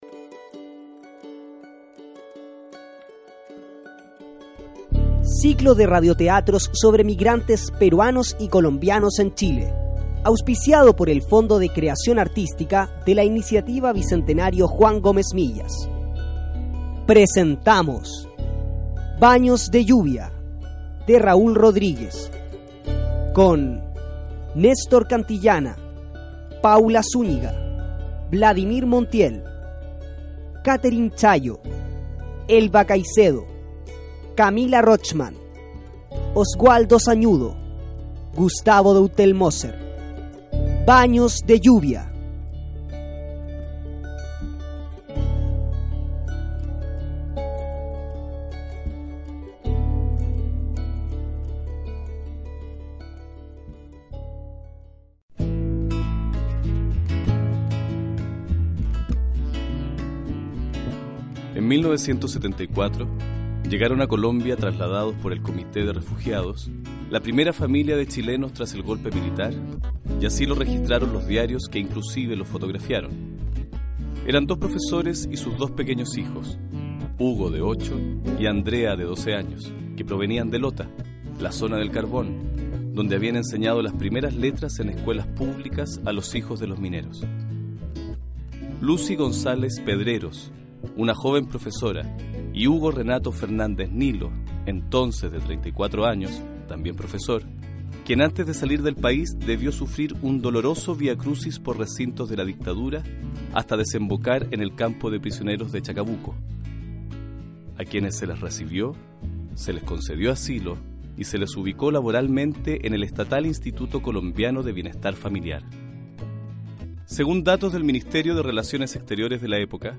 Comienza transmisión de ciclo de radioteatros con y sobre migrantes de Radio Juan Gómez Milla - Mapuexpress